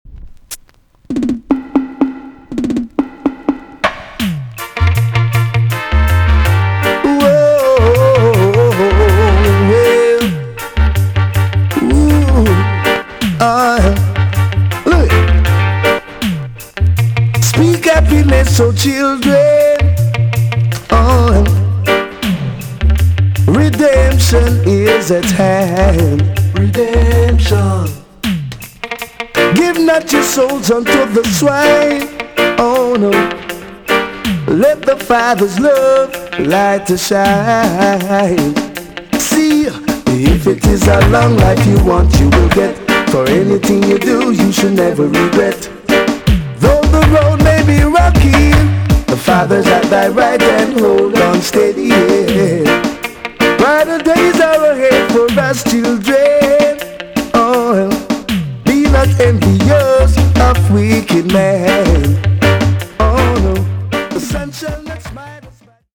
TOP >80'S 90'S DANCEHALL
EX- 音はキレイです。
RARE , NICE VOCAL TUNE!!